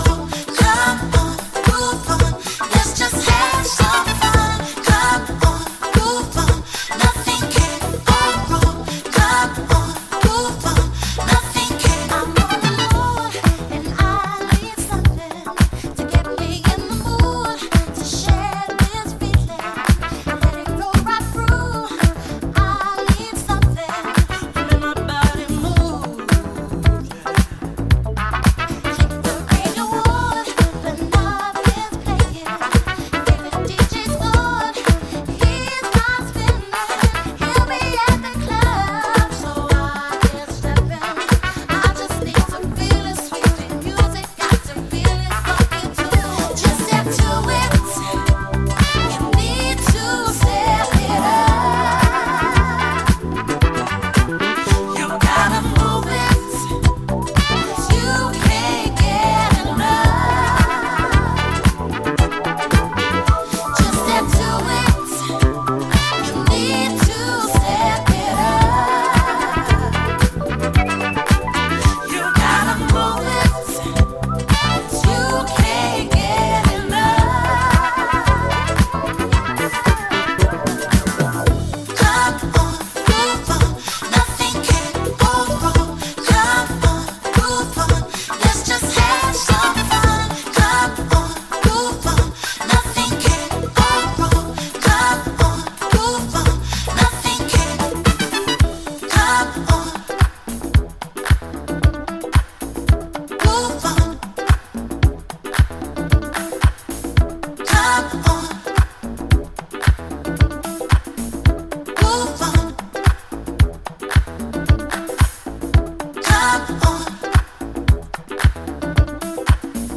Classic Boogie version